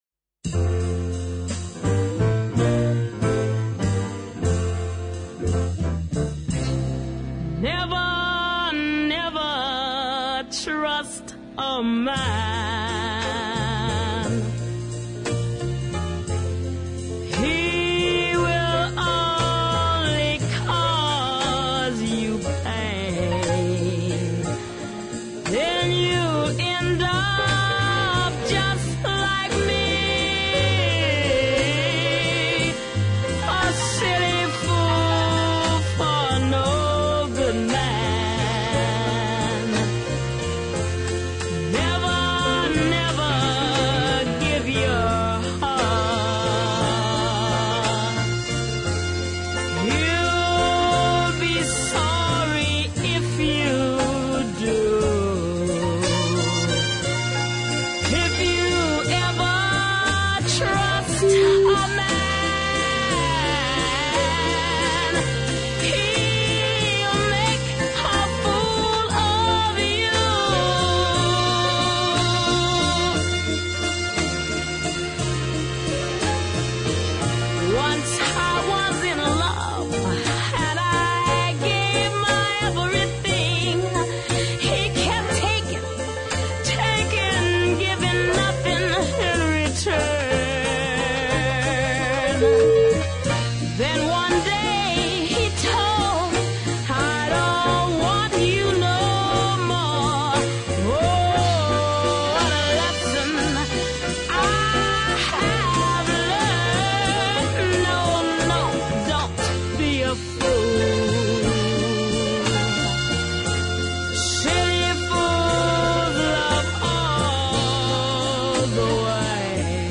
gives a spirited performance